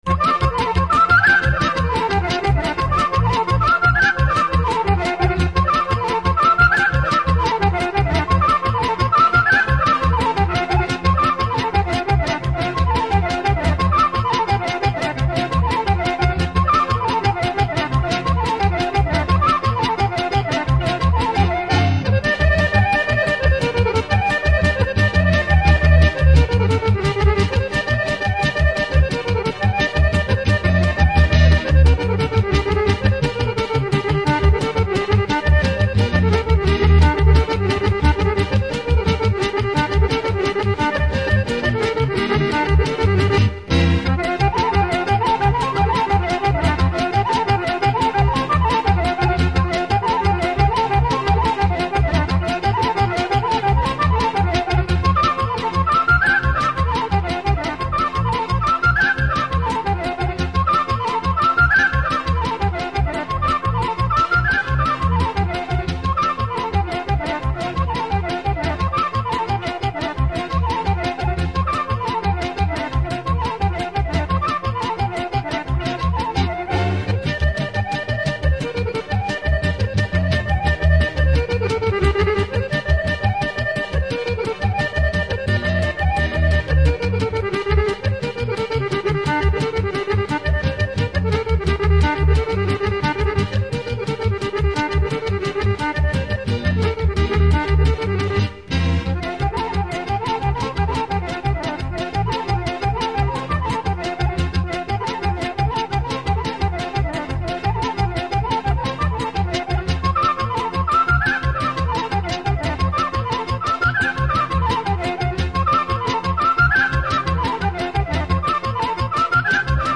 Iz fonoteke Muzeja u Majdanpeku:
50 bisera vlaške muzike!